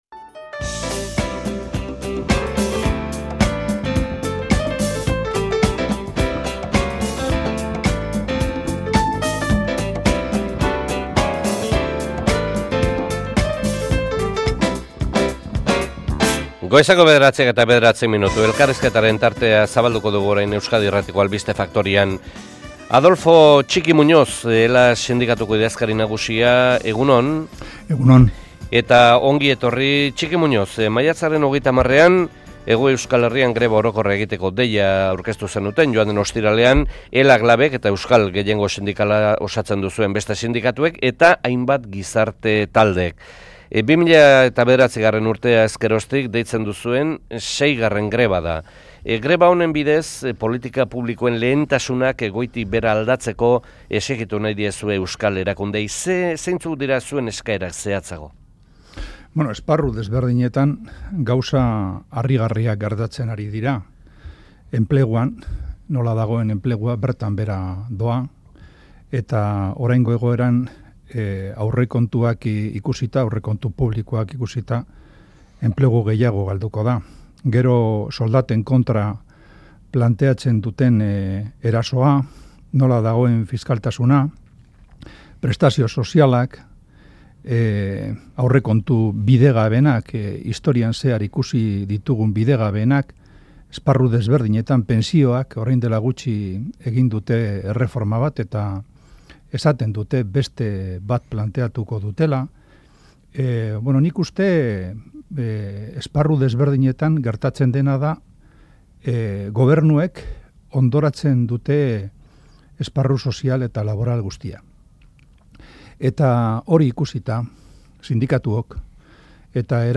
elkarrizketa | Maiatzaren 30eko greba orokorra